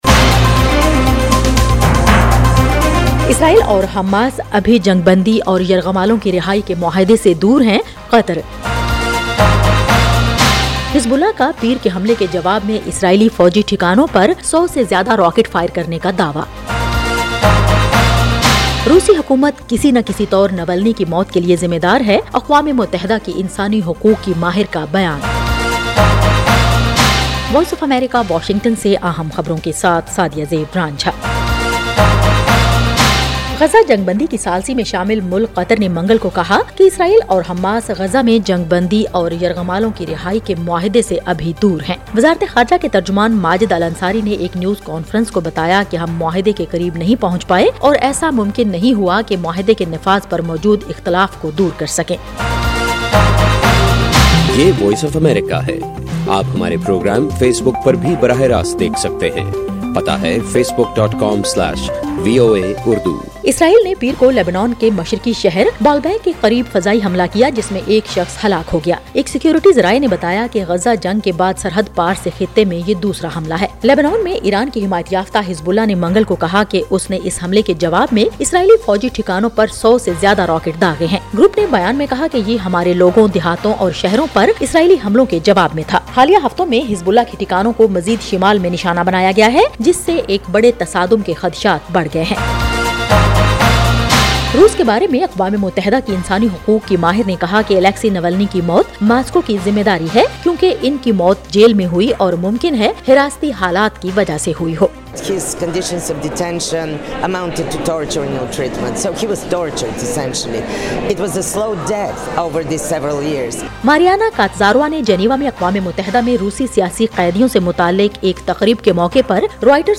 ایف ایم ریڈیو نیوز بلیٹن :شام 7 بجے